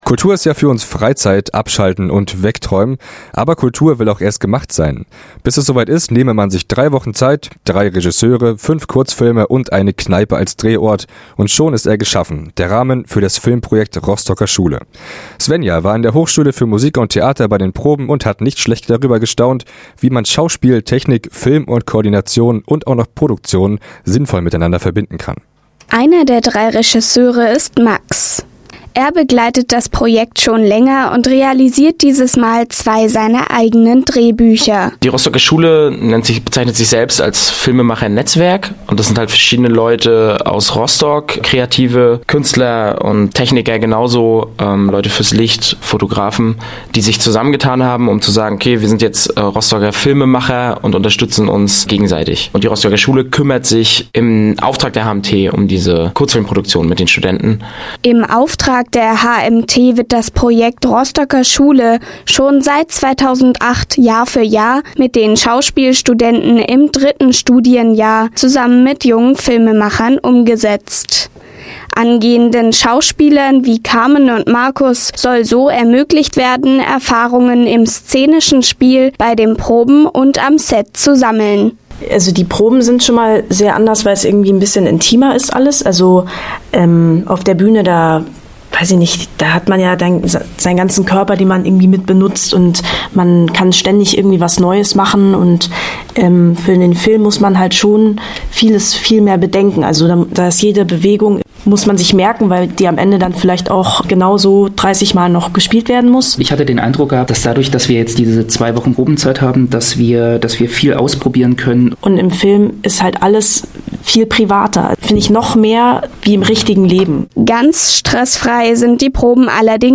Im Gespräch mit Filmschaffenden